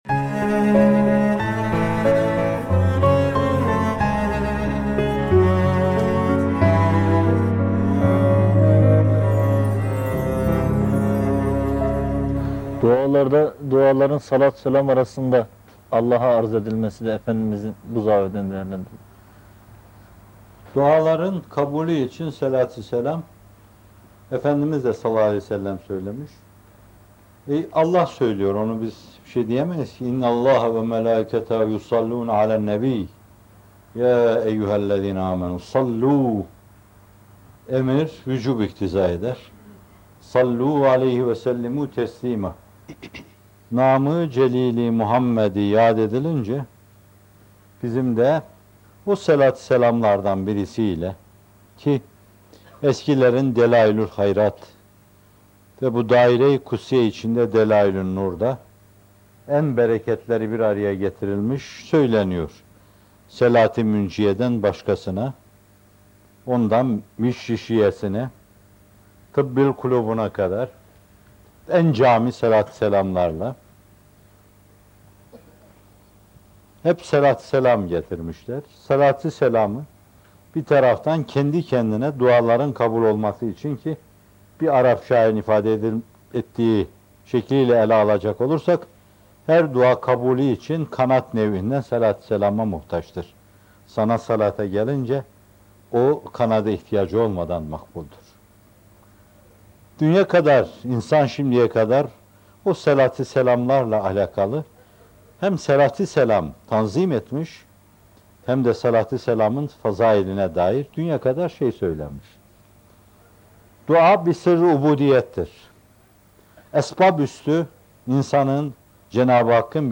Dua Bir Kulluk Sırrıdır: Salavatla Yükselen Yakarışlar - Fethullah Gülen Hocaefendi'nin Sohbetleri